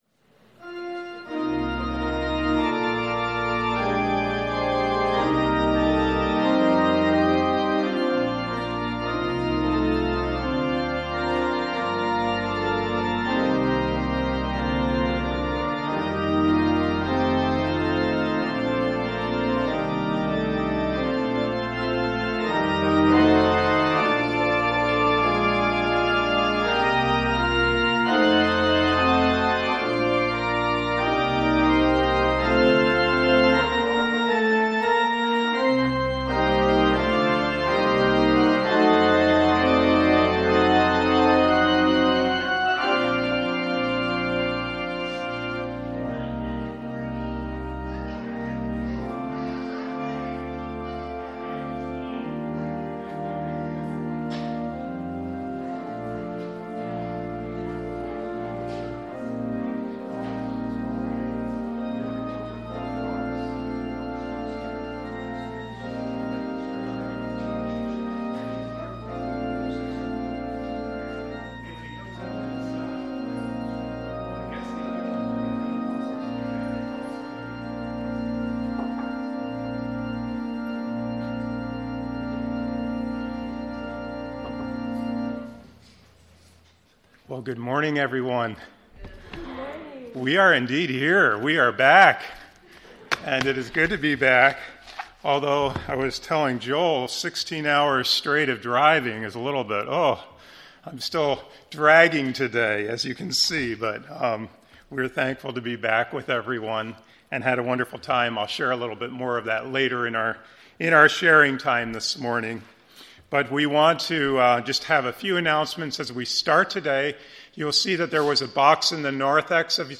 The services we post here were preached the previous week.